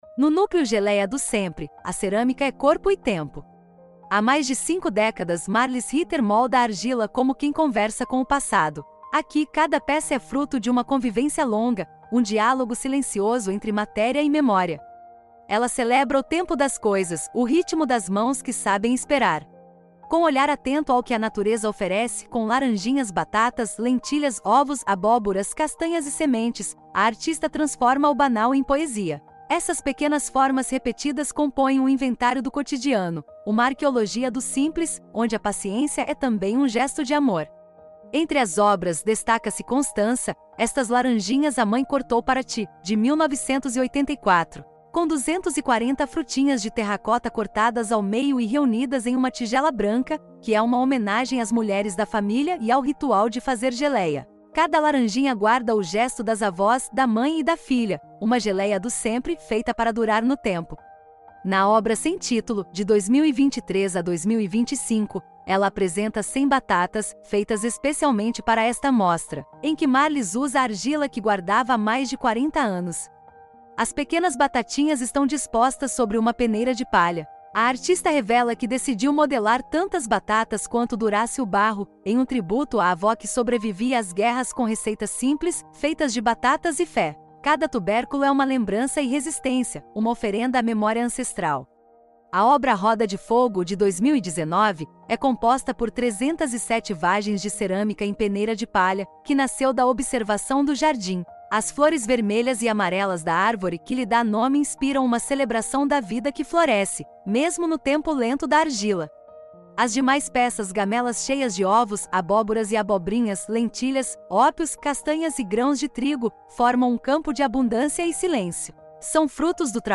Audiodescrição do Núcleo 3 | Geleia do Sempre